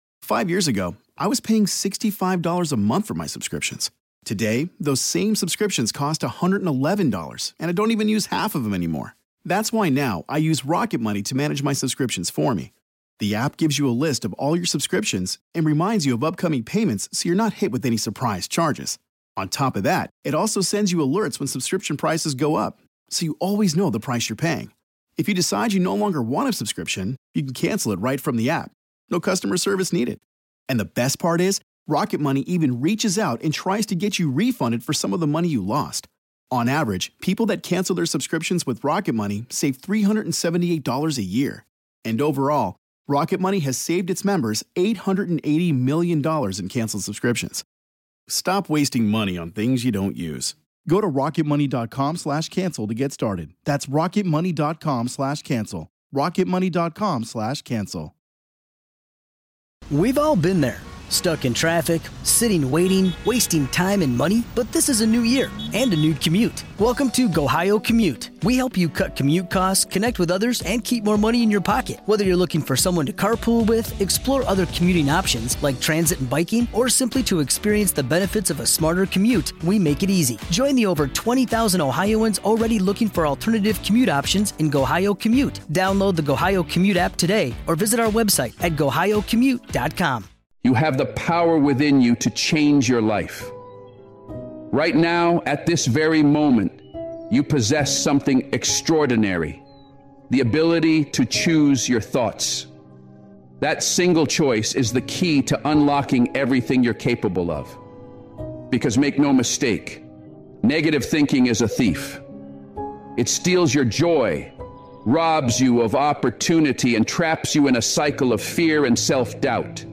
This speech is a call to war against that voice.